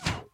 Plants vs. Zombies sounds (звуки из игры) Часть 3
throw2.mp3